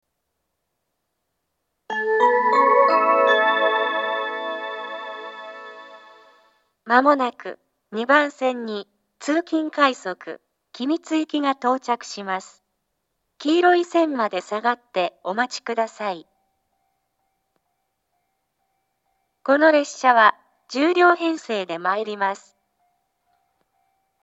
２番線接近放送 通勤快速君津行（１０両）の放送です。